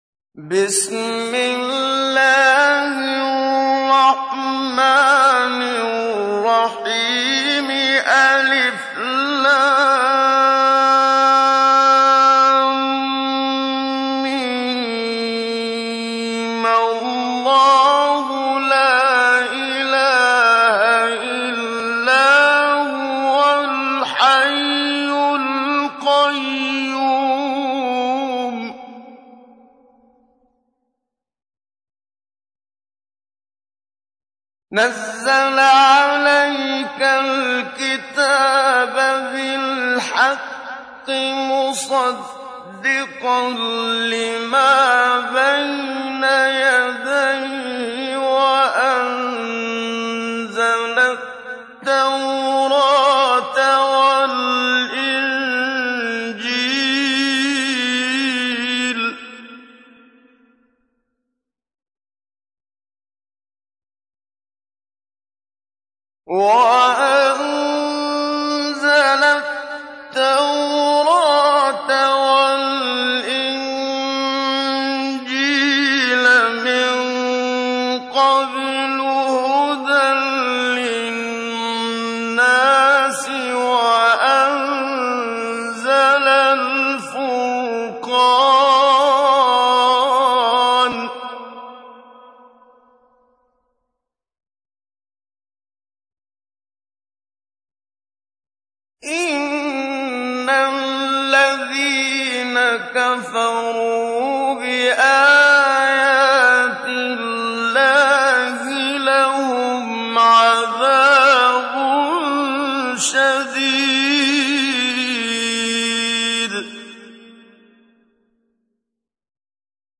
تحميل : 3. سورة آل عمران / القارئ محمد صديق المنشاوي / القرآن الكريم / موقع يا حسين